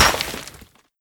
BulletImpact_Concrete03.wav